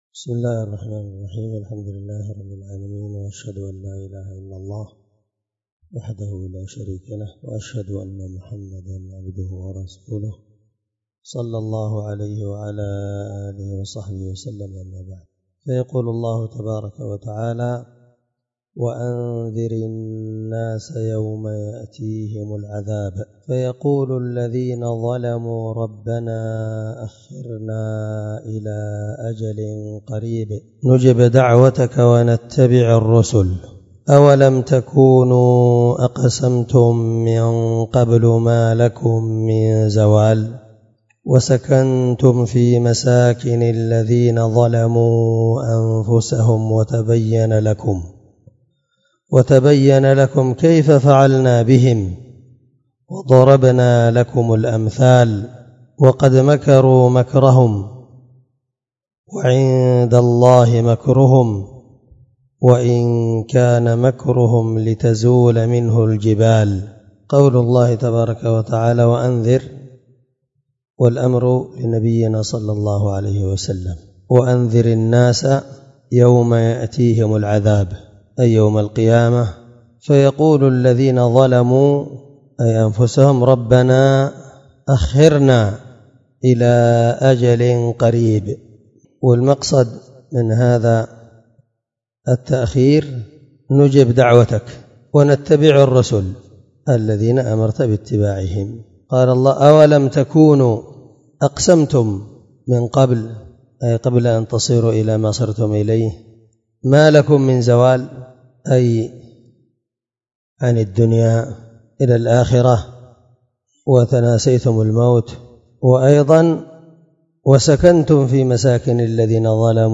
708الدرس15تفسير آية (44-46) من سورة إبراهيم من تفسير القرآن الكريم مع قراءة لتفسير السعدي
دار الحديث- المَحاوِلة- الصبيحة.